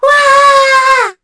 Kara-Vox_Happy4_kr.wav